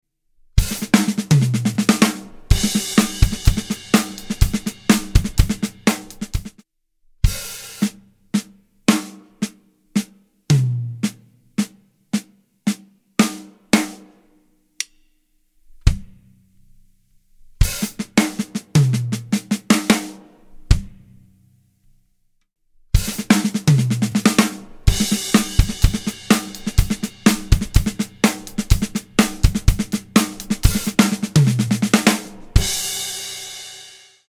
Simple Six Stroke Roll Fill🥁 sound effects free download